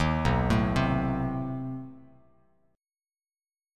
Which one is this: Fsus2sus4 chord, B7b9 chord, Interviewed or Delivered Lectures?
B7b9 chord